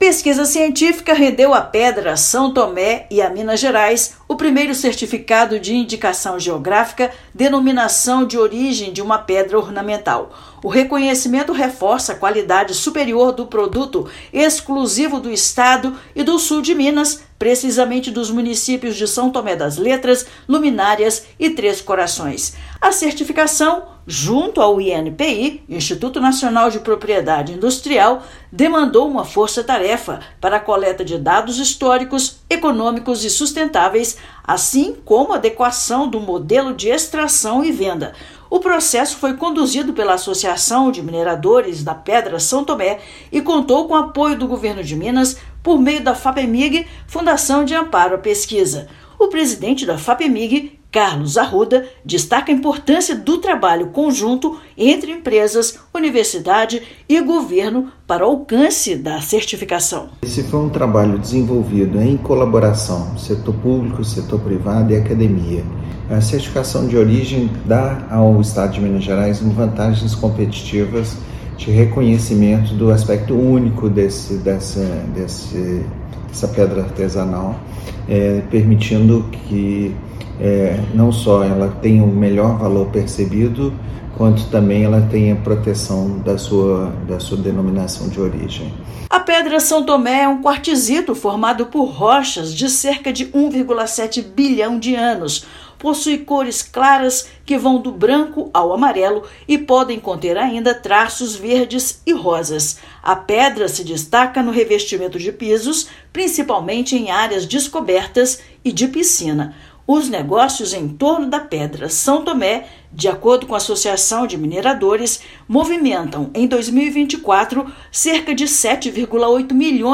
Certificado de Denominação de Origem é fruto de parceria entre empresas da região, universidade e Governo de Minas. Ouça matéria de rádio.